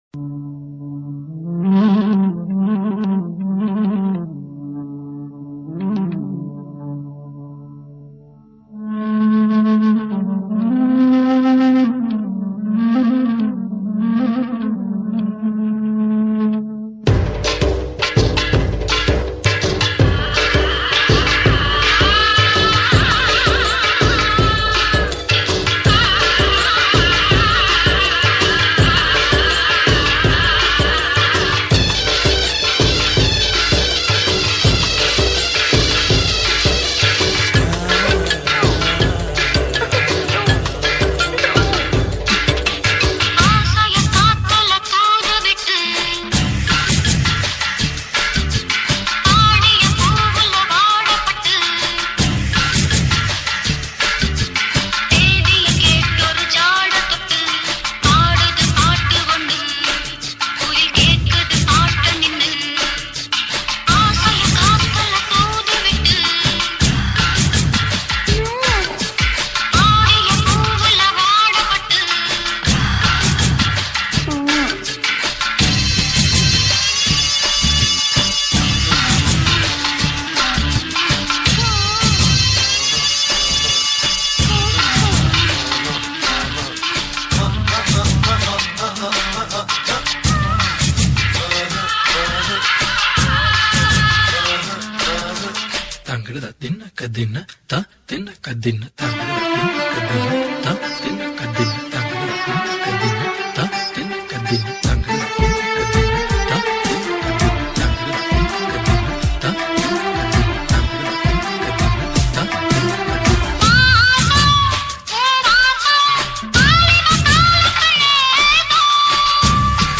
ALL TAMIL ROMANTIC DJ REMIX